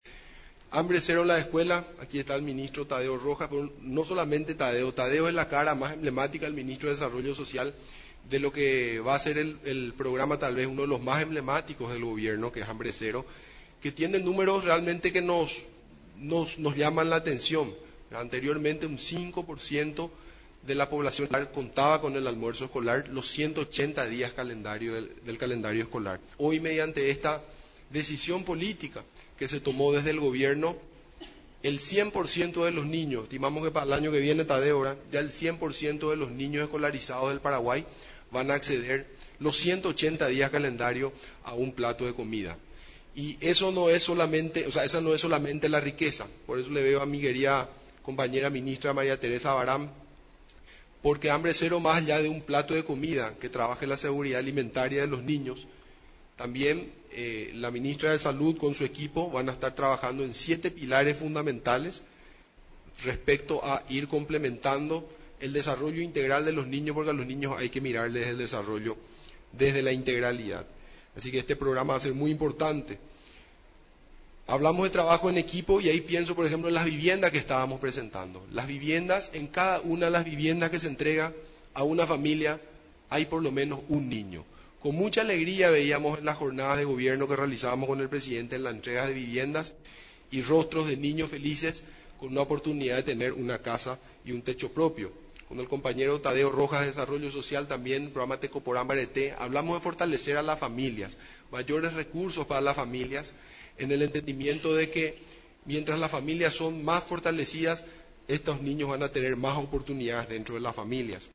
En el informe de Gestión, realizado en la Residencia Presidencial de Mburivichá Róga, estuvo presente en representación del Ejecutivo, la Primera Dama de la Nación, Leticia Ocampos, además de otras autoridades nacionales.